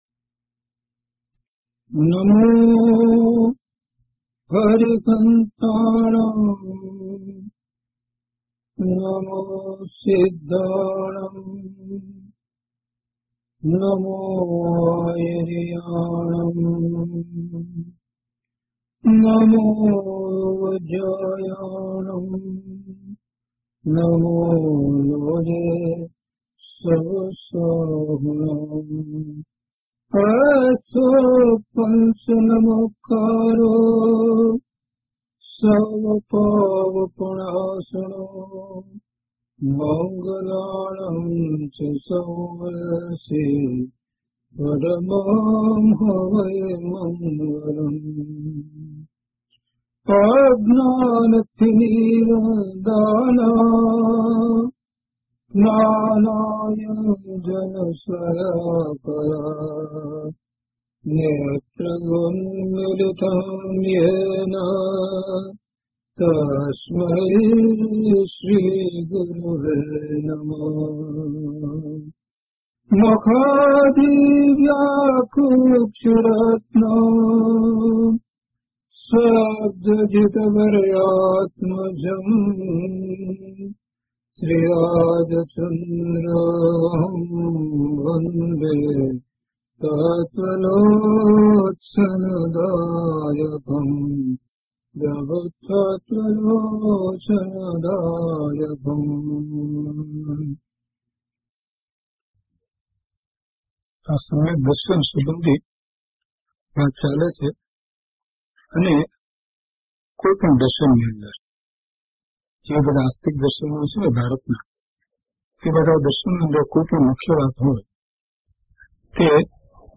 Audio title: DHP078 Samyag Darshan (Chha Pad) part-3 - Pravachan.mp3
DHP078 Samyag Darshan (Chha Pad) part-3  - Pravachan.mp3